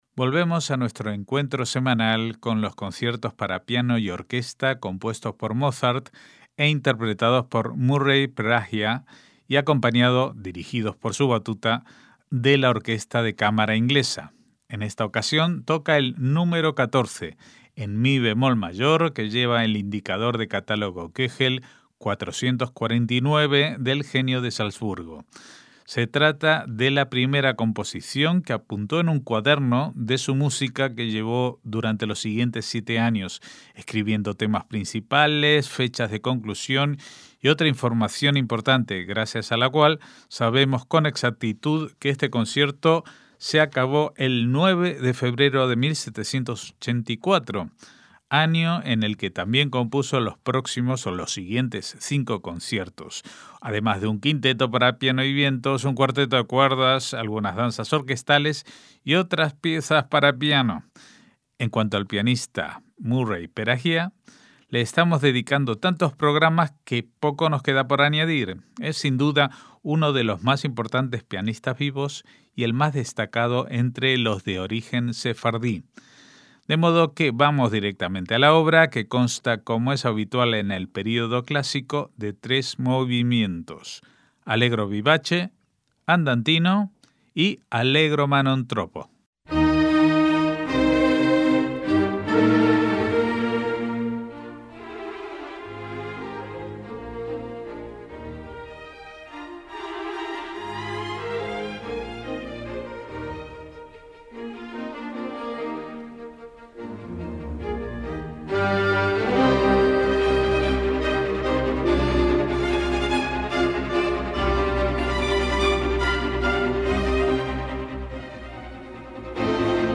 MÚSICA CLÁSICA - Volvemos a nuestro encuentro semanal con los conciertos para piano y orquesta compuestos por Mozart e interpretados por Murray Perahia y acompañado, dirigidos por su batuta, de la Orquesta de Cámara Inglesa. En esta ocasión toca el Nº 14 en mi bemol mayor, que lleva el indicador de catálogo Köchel 449 del genio de Salzburgo.